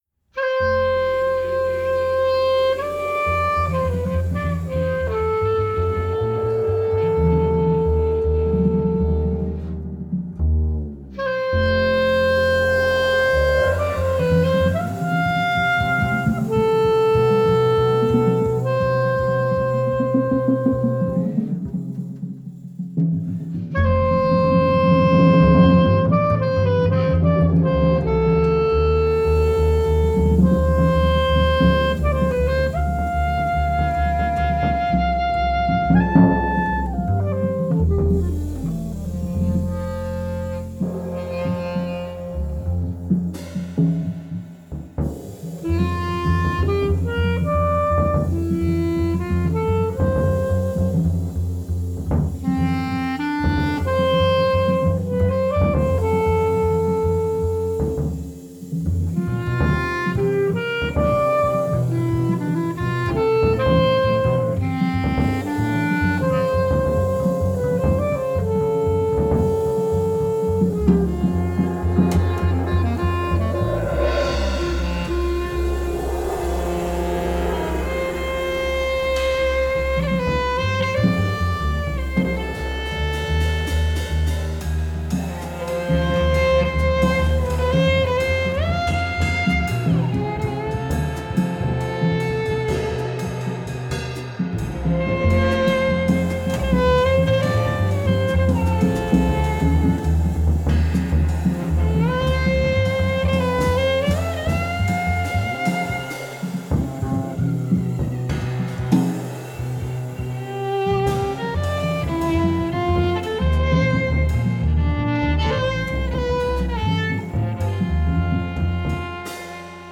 Geige, Gesang
Tenorsaxophon, Klarinette
Kontrabass
Schlagzeug